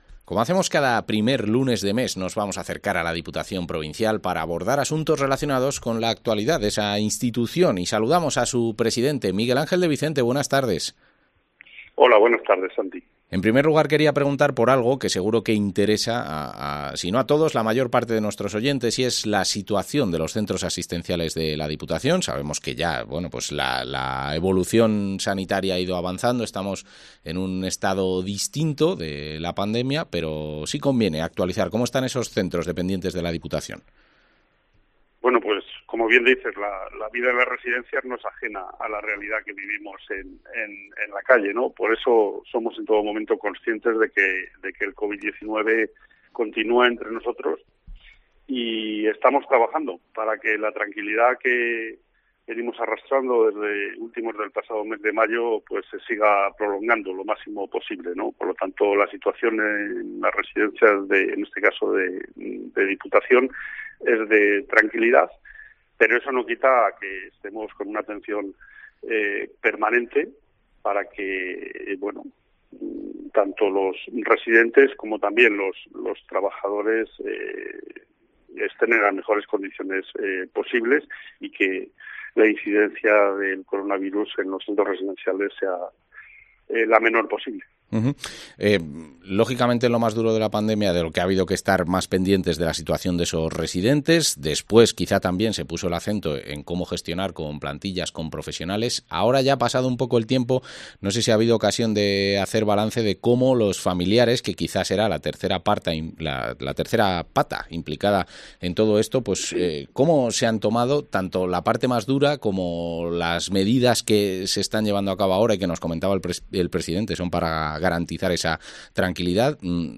Entrevista al presidente de la Diputación de Segovia, Miguel Ángel de Vicente